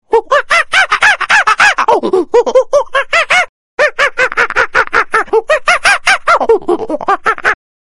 Kategorien: Tierstimmen